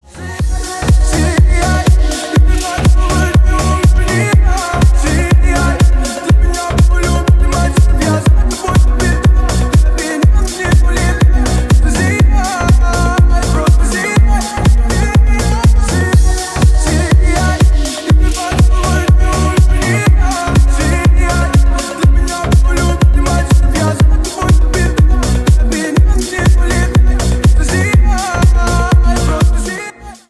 Ремикс # Танцевальные
громкие